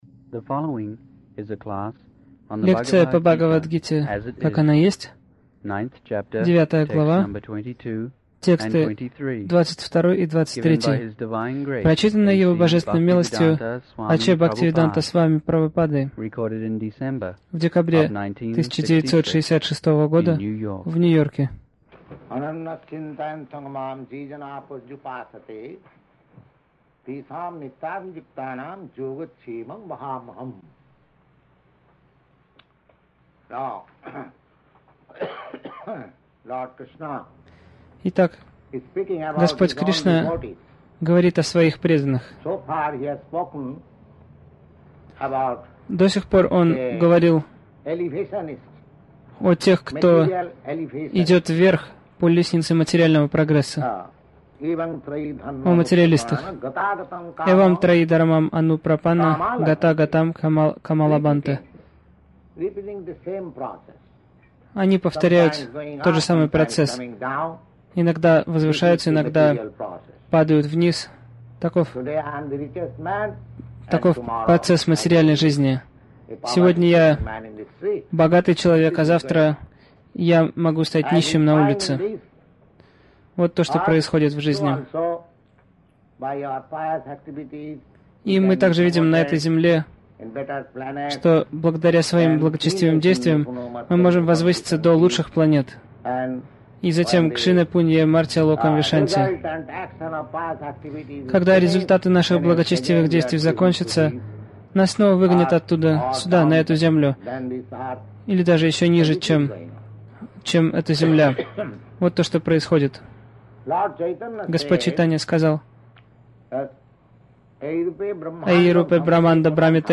Милость Прабхупады Аудиолекции и книги 09.12.1966 Бхагавад Гита | Нью-Йорк БГ 09.22-23 Загрузка...